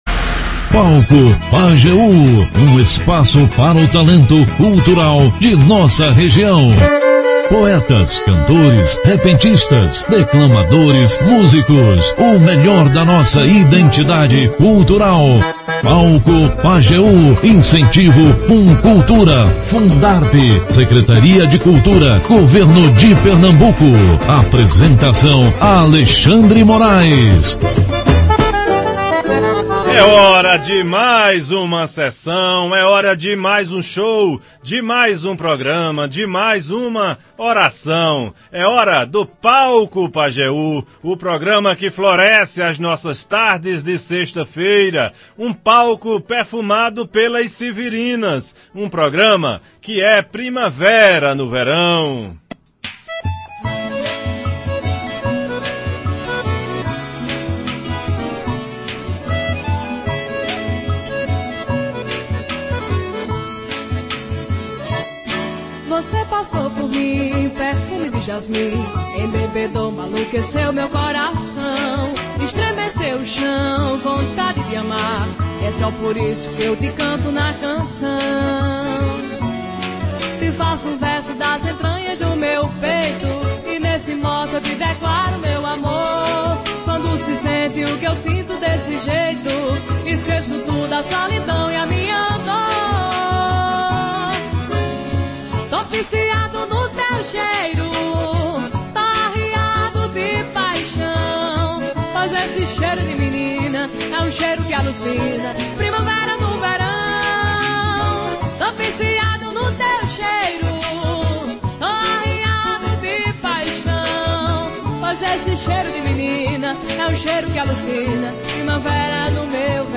O Palco Pajeú é um espaço para o talento cultural da região, com poetas, cantores, repentistas, declamadores, músicos e o melhor da identidade cultural do sertanejo.